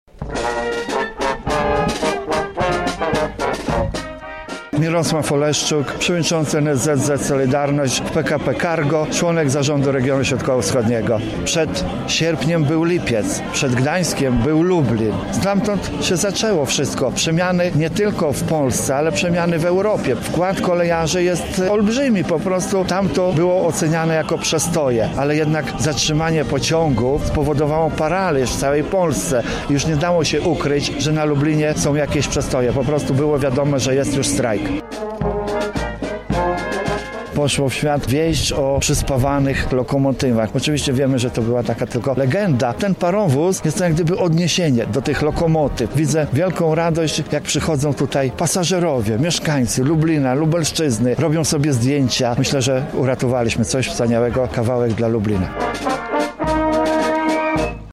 W niedzielę władze, mieszkańcy miasta oraz uczestnicy tamtych wydarzeń upamiętnili rocznicę Lubelskiego Lipca. Na miejscu była nasza reporterka: